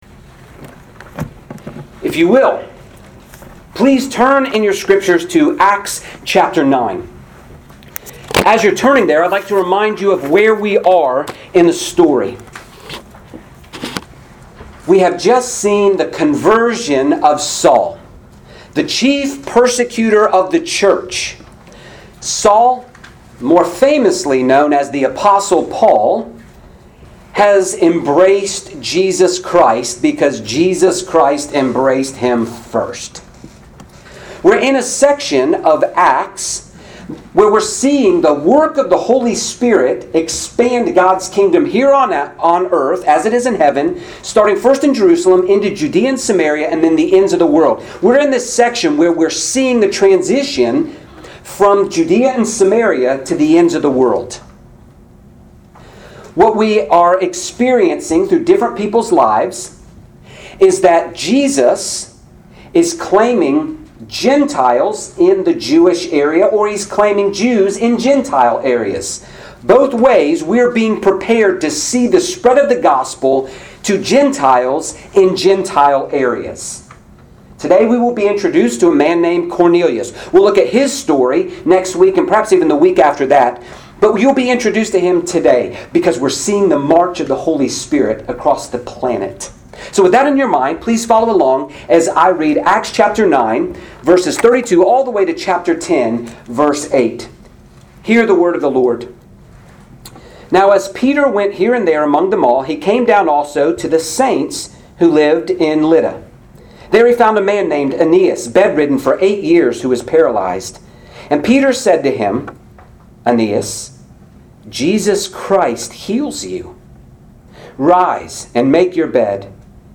Grace Presbyterian Church, PCA Sermons